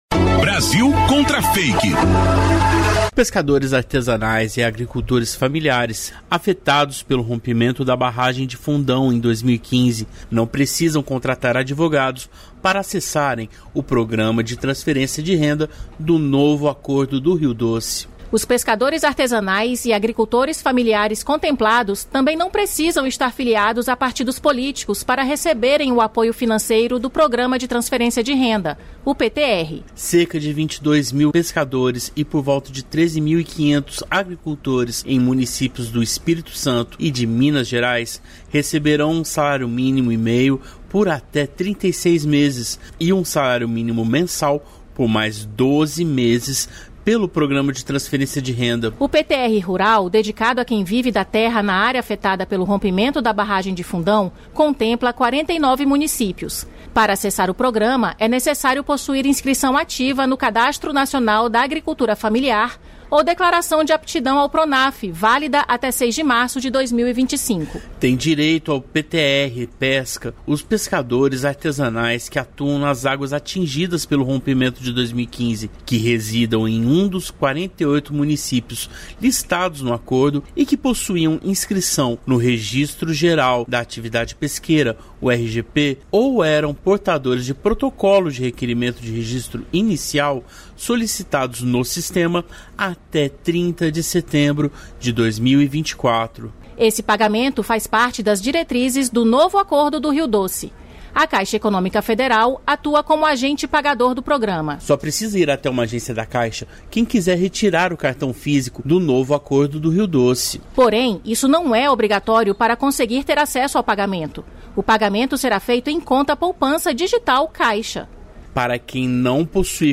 O IOF, Imposto sobre Operações Financeiras, não incide sobre o Pix, e nenhum outro tributo. Ouça o boletim e entenda.